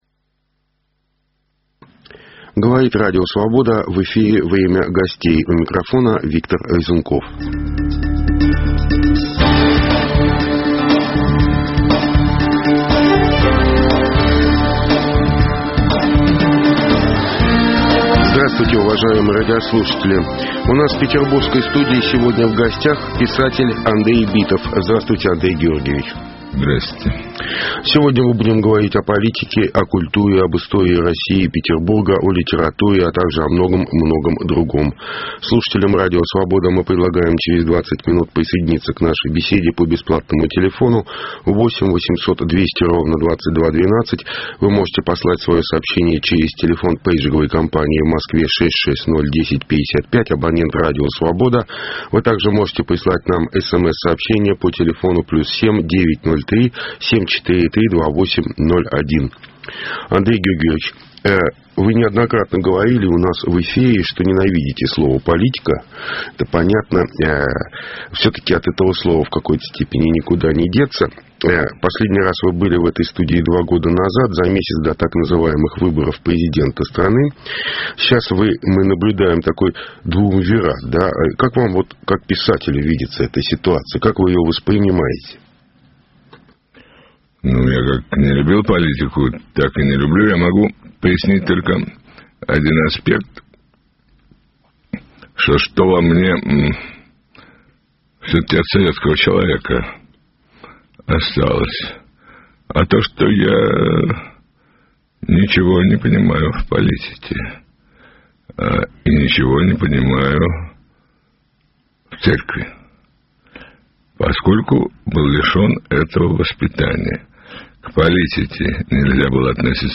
О политике и культуре, об исторических путях России и Петербурга беседуем с писателем Андреем Битовым.